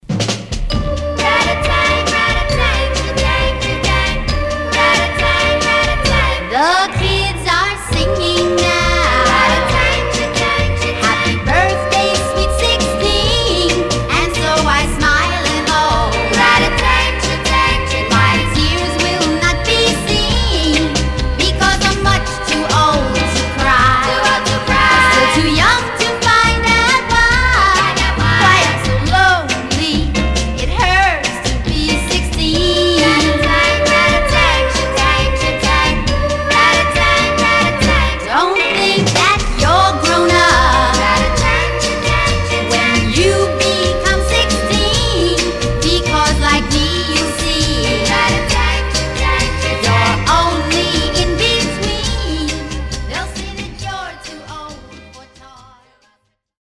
Surface noise/wear Stereo/mono Mono
White Teen Girl Groups